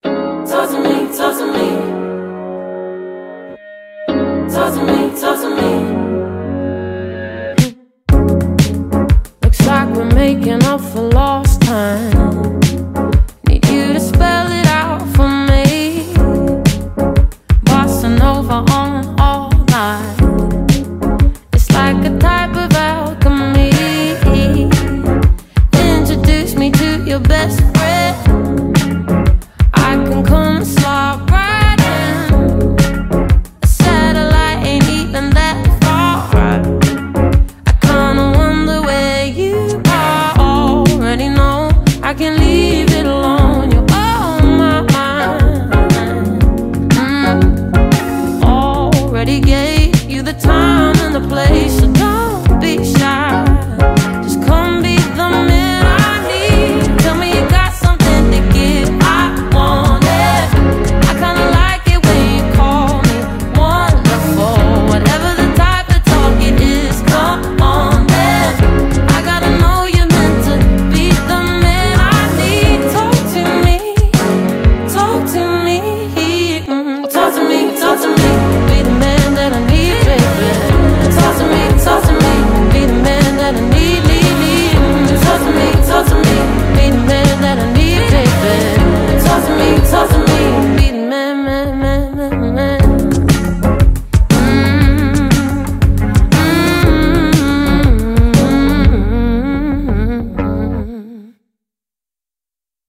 BPM119
Audio QualityPerfect (Low Quality)